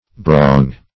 Search Result for " barong" : Wordnet 3.0 NOUN (1) 1. a knife resembling a cleaver ; used in the Philippines ; The Collaborative International Dictionary of English v.0.48: Barong \Ba*rong"\, n. [Native name.]